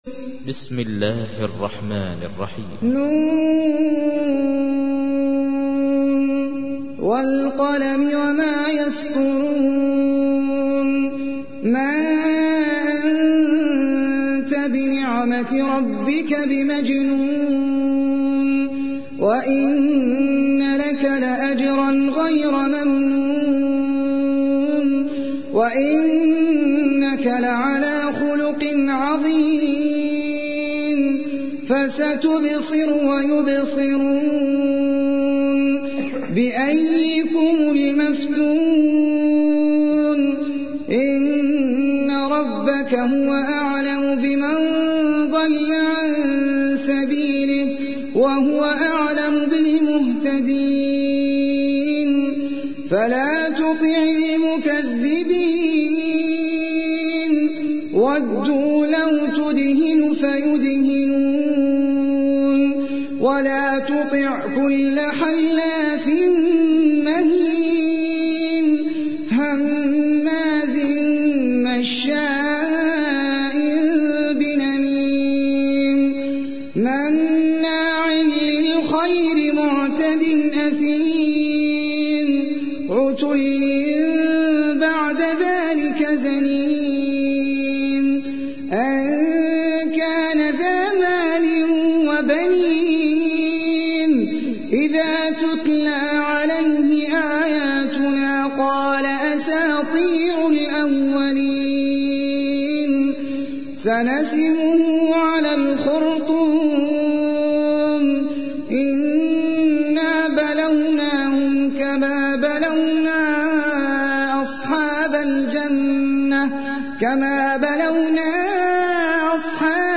تحميل : 68. سورة القلم / القارئ احمد العجمي / القرآن الكريم / موقع يا حسين